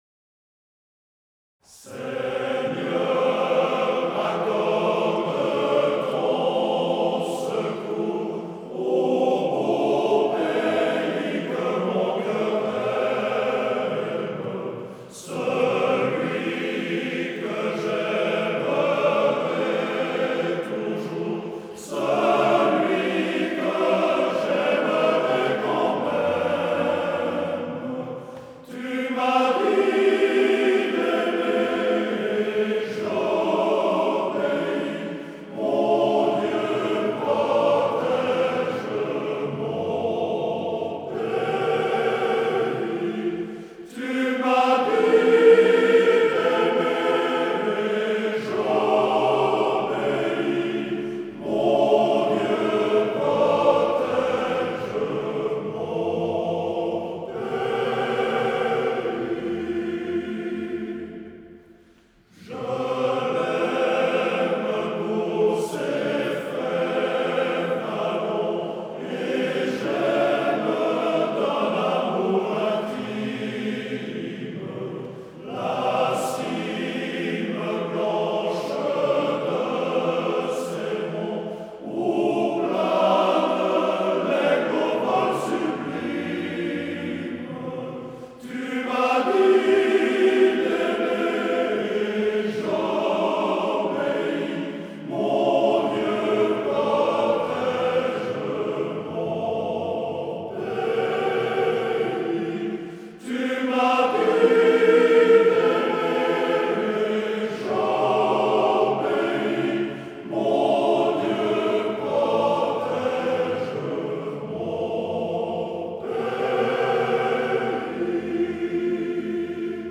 Version originale
par Chorale du Brassus
H10101-Live.flac